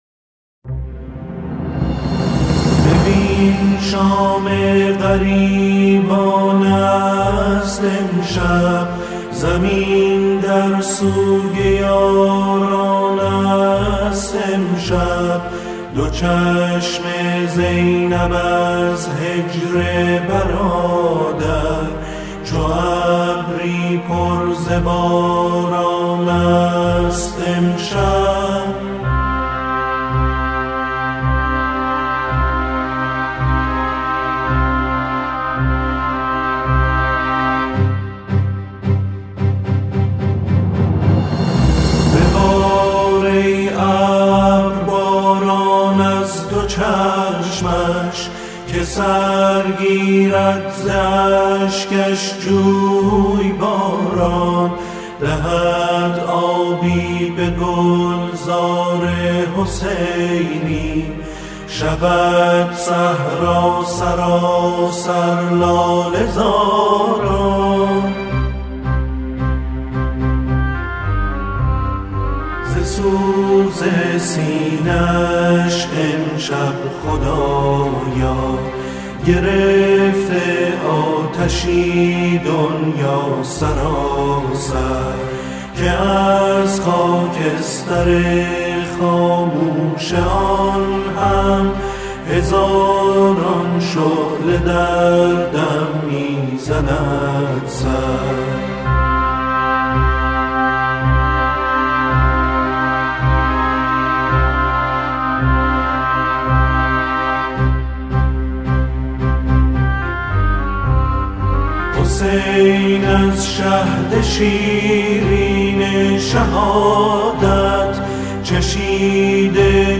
اجرای گروه کر، در جای جای این اثر، پررنگ و قابل توجه است.